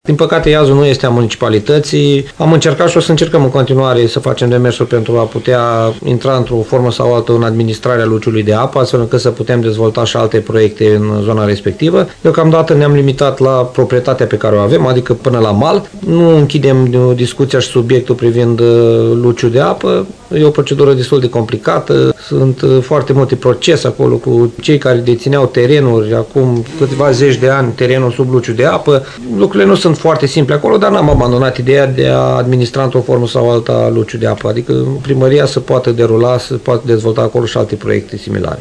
Primarul CĂTĂLIN COMAN a declarat că baza de agrement din apropiere ar putea fi astfel completată cu alte proiecte de dezvoltare.